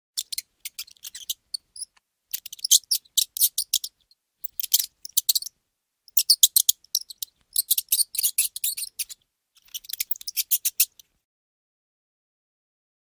دانلود آهنگ موش 2 از افکت صوتی انسان و موجودات زنده
جلوه های صوتی
دانلود صدای موش 2 از ساعد نیوز با لینک مستقیم و کیفیت بالا